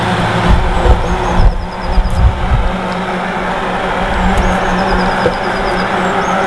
Vogelstimme und Verkehrslärm
passend zum linken Bildausschnitt aus der Mitte, Meise und Verkehrslärm sind noch gut zu trennen
Der Verkehrslärm ist leiser.
Tondatei der Meise und Verkehrslärm
auto-vogel-001-a-22m.wav